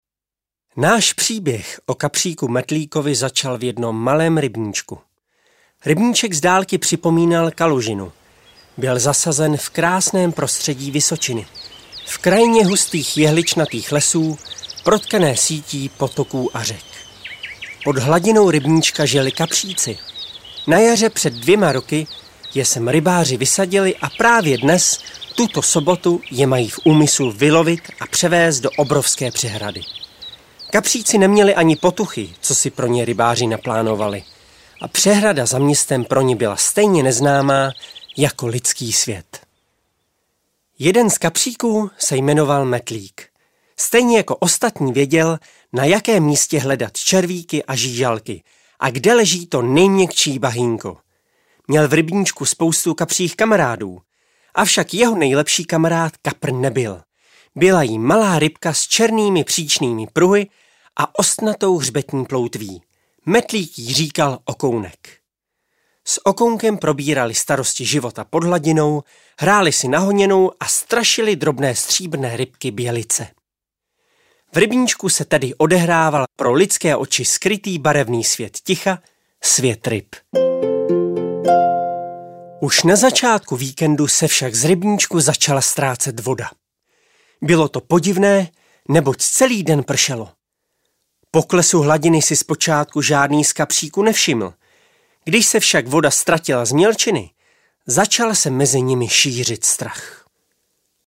Dlouho očekávaná audiokniha prvního dílu Kapříka Metlíka namluvená Jakubem Vágnerem. Ponořte se pod hladinu a nechte se unést pohádkovým příběhem malého kapříka, kouzelnými melodiemi a samozřejmě nezapomenutelným hlasem Jakuba Vágnera.